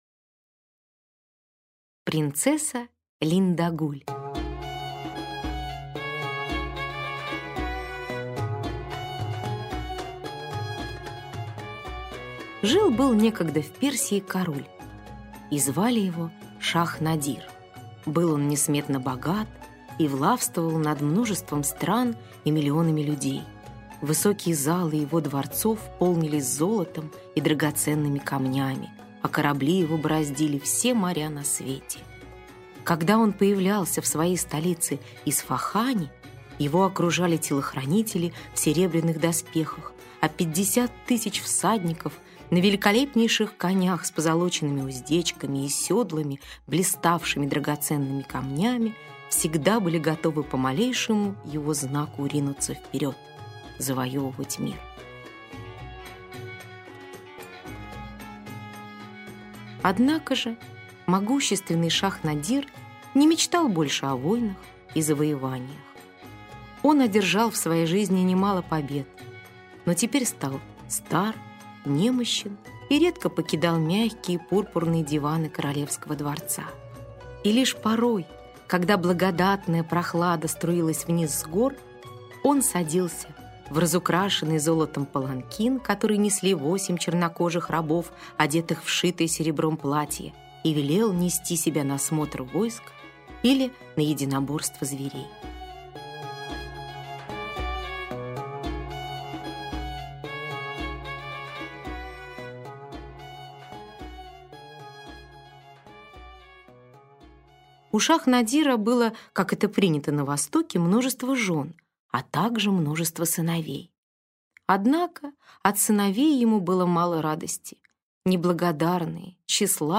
Принцесса Линдагуль - аудиосказка Сакариаса Топелиуса. Сказка про юную принцессу Линдагуль, любимицу Шах Надира.